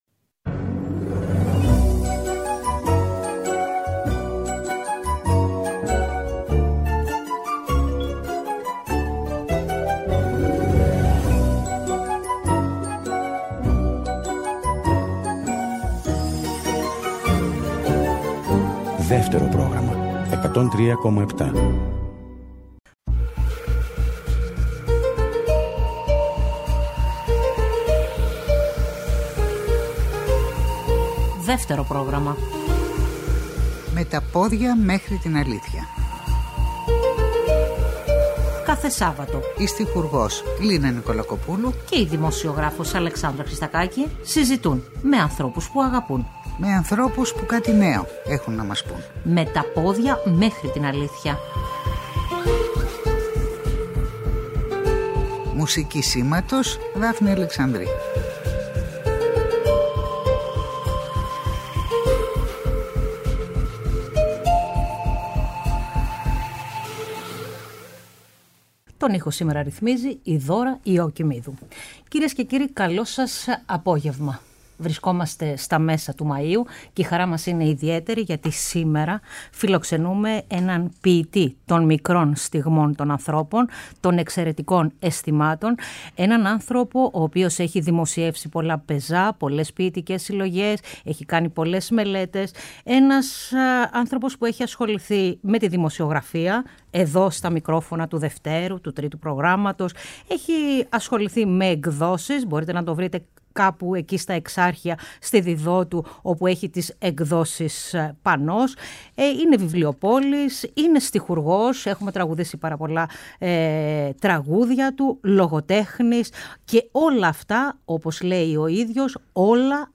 Καλεσμένος ο Γιώργος Χρονάς.